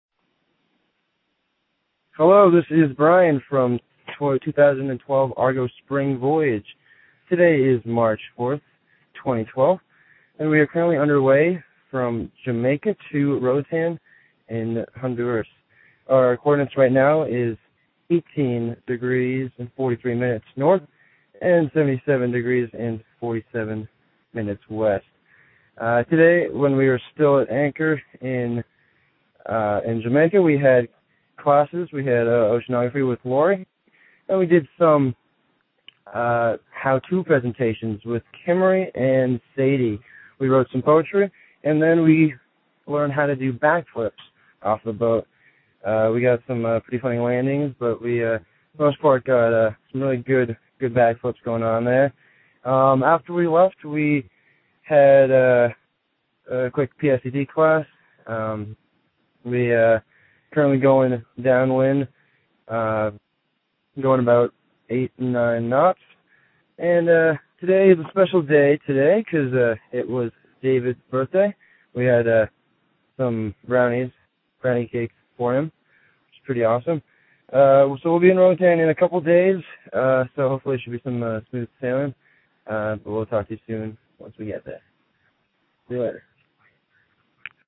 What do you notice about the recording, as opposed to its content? The Argo crew calls in from on passage between Jamaica and Roatan.